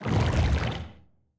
Sfx_creature_bruteshark_swim_slow_01.ogg